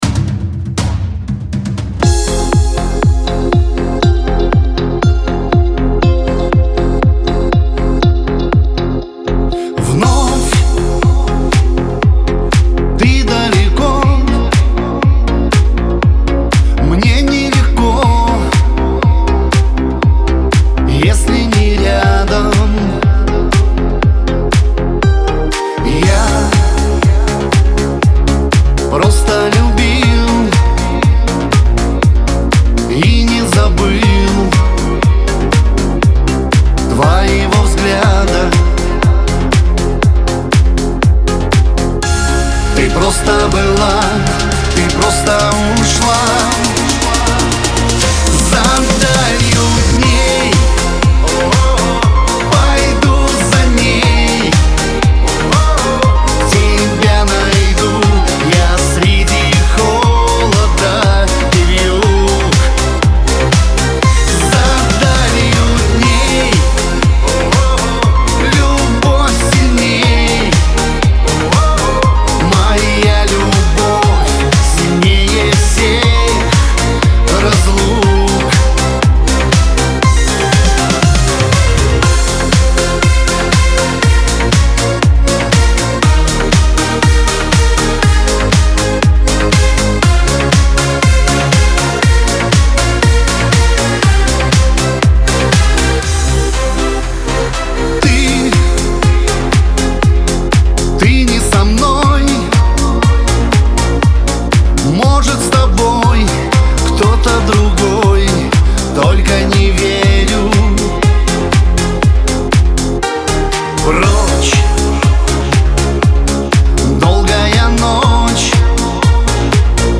эстрада
диско